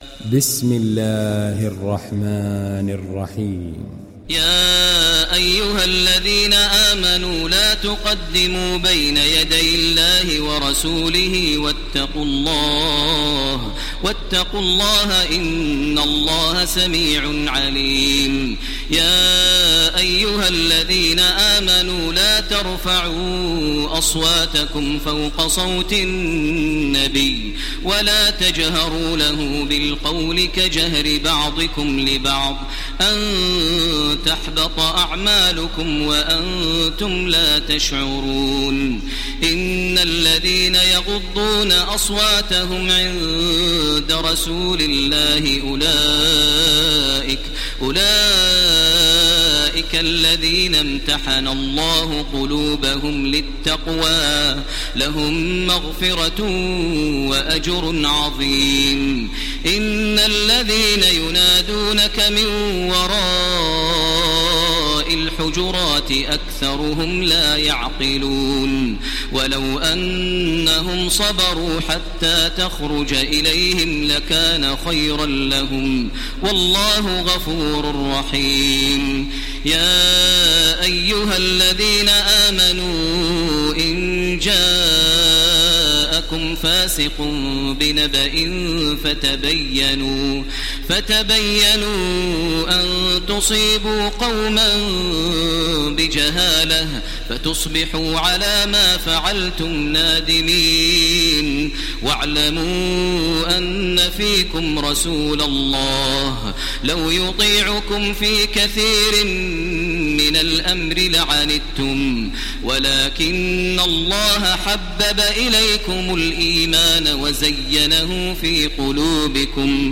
İndir Hucurat Suresi Taraweeh Makkah 1430